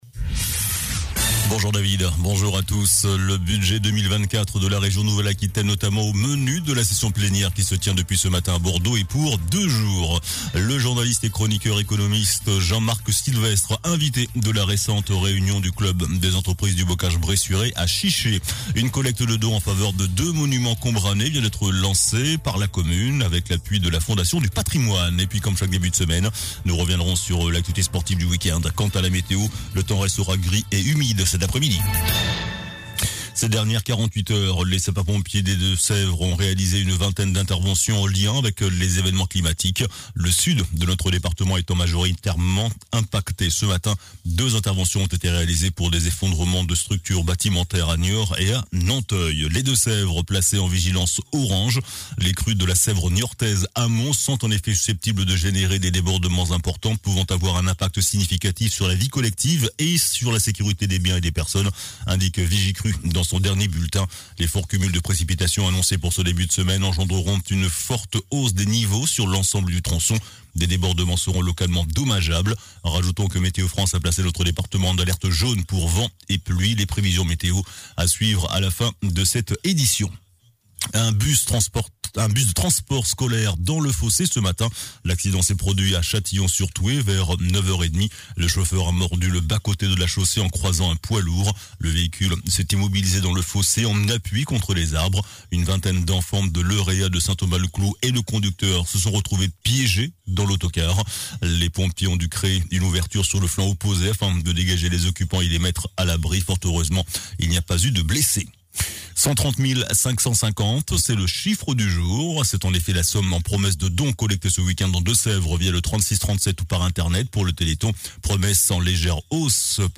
JOURNAL DU LUNDI 11 DECEMBRE ( MIDI )